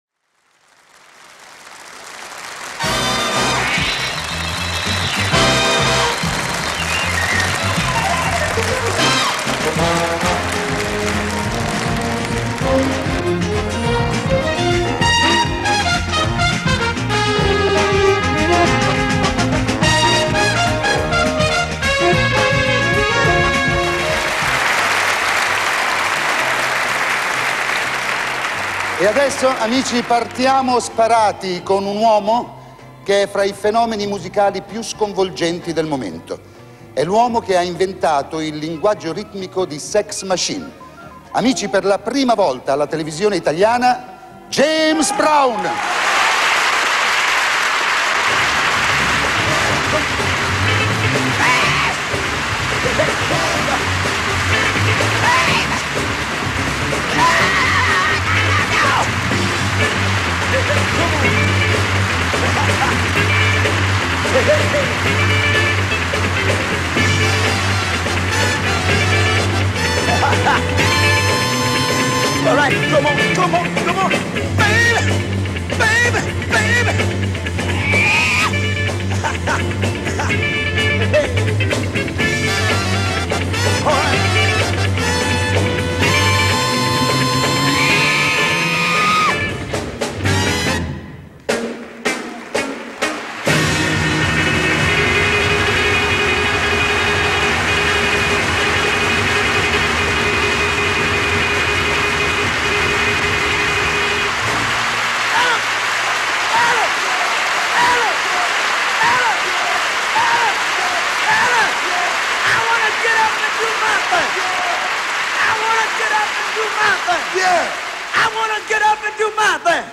live in Rome
Sounds of Soul In session